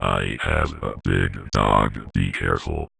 VVE1 Vocoder Phrases
VVE1 Vocoder Phrases 29.wav